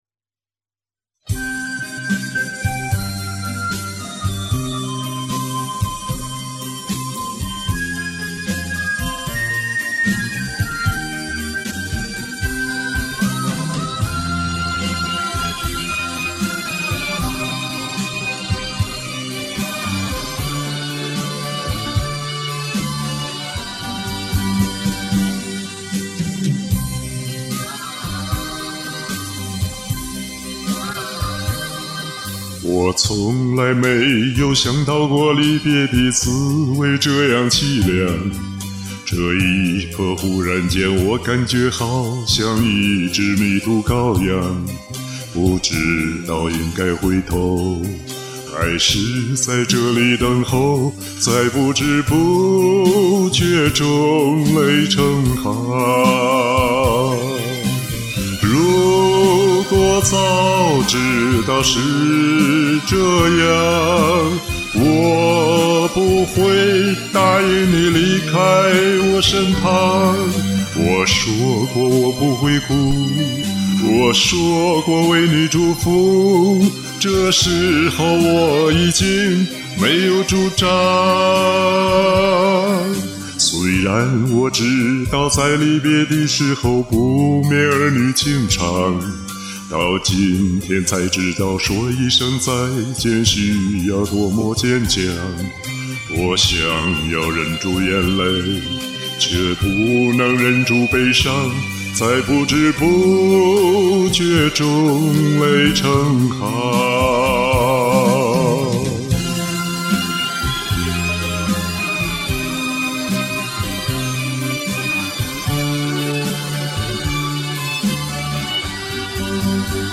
但找到的伴奏后发现这歌速度贼快， 结果是上气不接下气一个劲儿的紧赶。
通俗歌曲唱得韵味十足，欣赏了！
好纯净的声音！凄美哀伤，把俺唱哭了：）